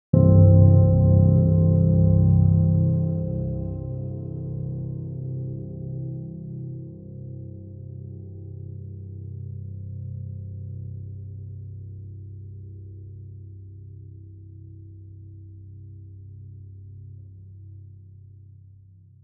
piano8.wav